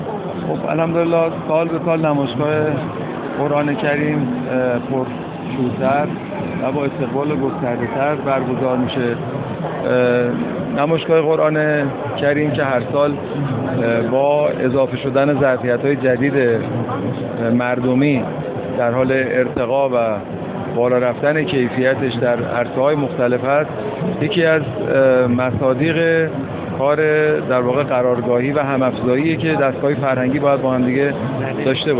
جبلی در گفت‌وگو با ایکنا: